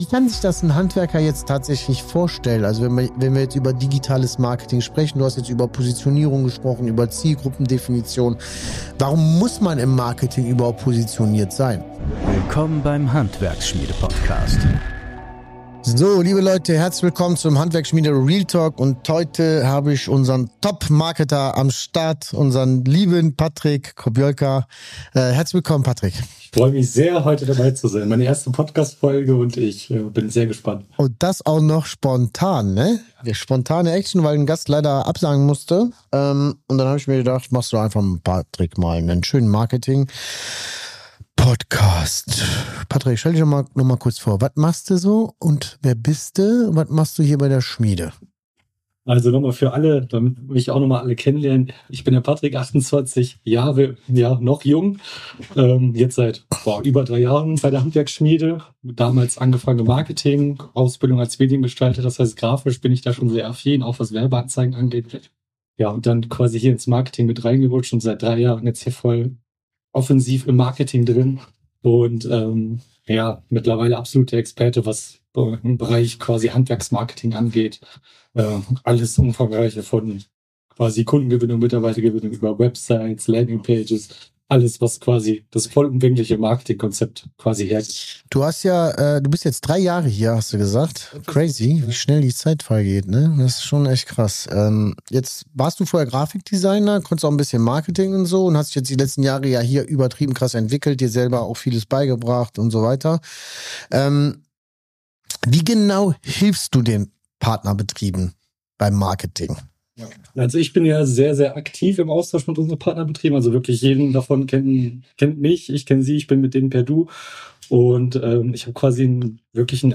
Wenn du 300€ ausgibst und 40.000€ zurückbekommst | Interview